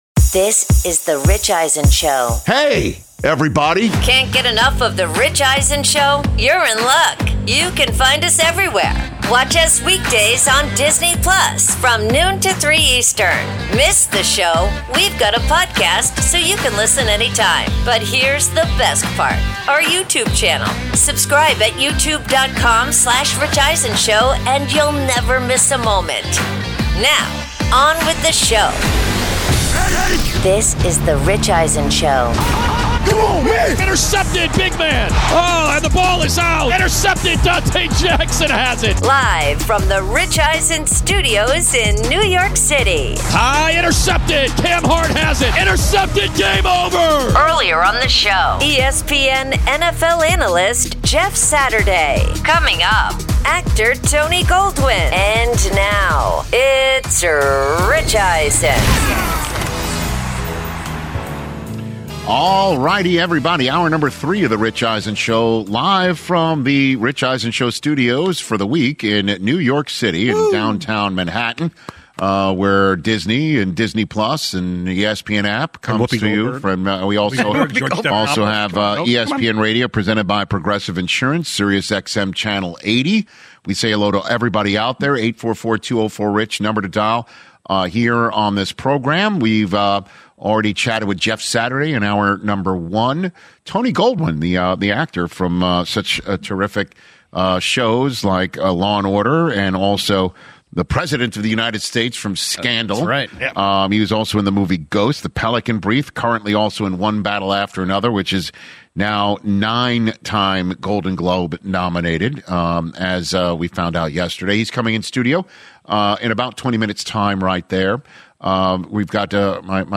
Hour 3: Justin Herbert Toughs It Out, Straight Outta Tomlin, plus Actor Tony Goldwyn In-Studio